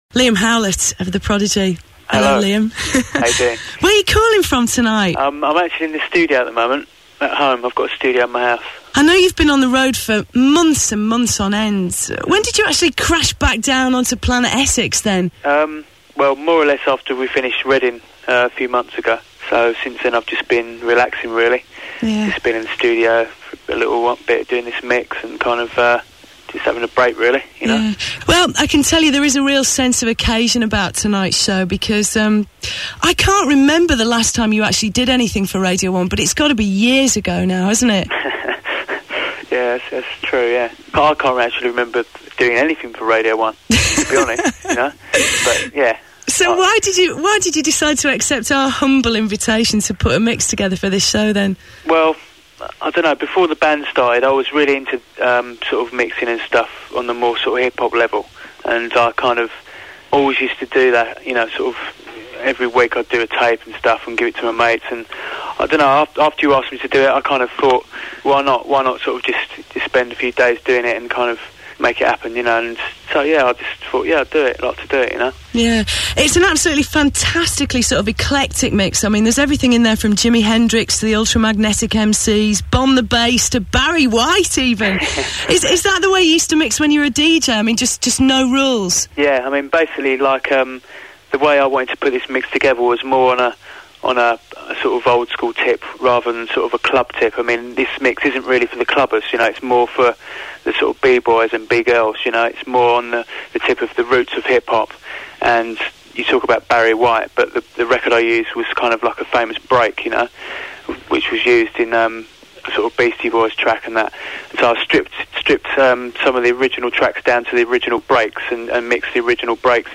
Liam Howlett interview on Radio 1
00_TheBreezeblock_interview.mp3